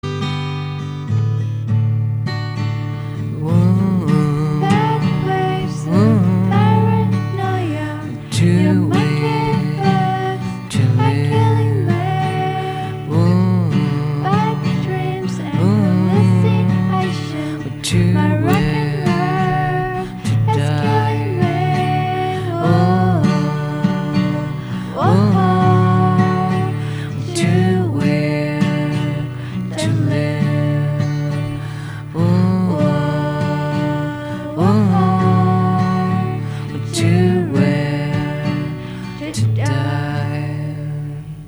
Indie-pop/rock/experimenta/lo-fi project